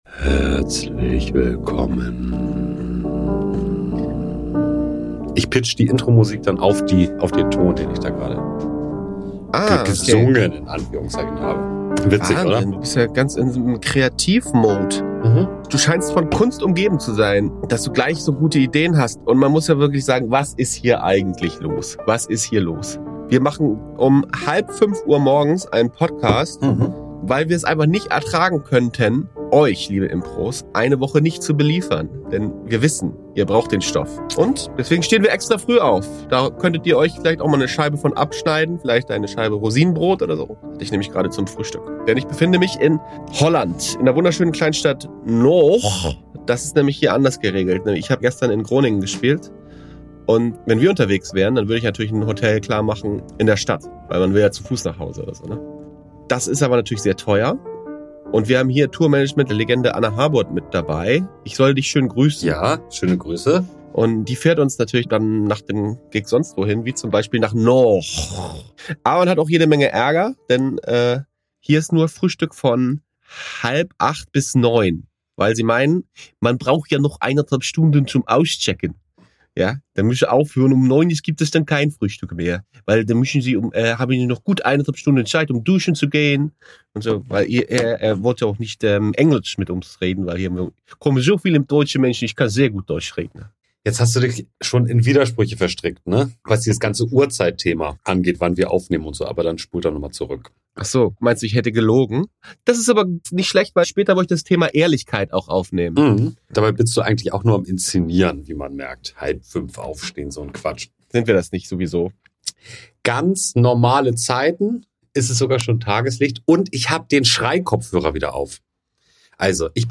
Aber klar doch, es wird durchgesendet, auch aus dem Hotelzimmer.